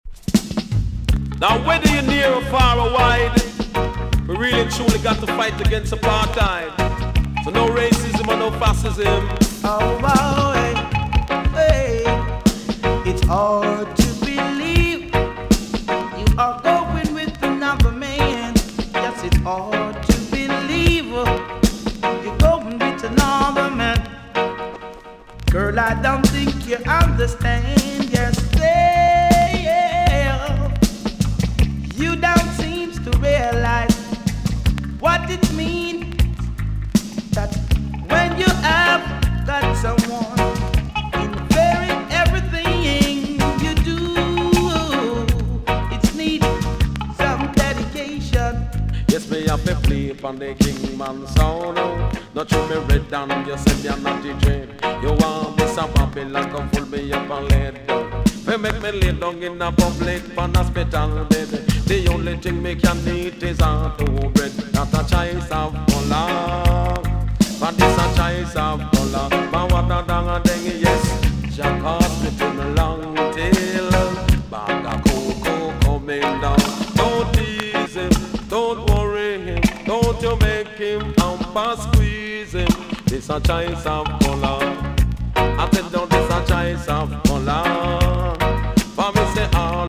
TOP >80'S 90'S DANCEHALL
EX- 音はキレイです。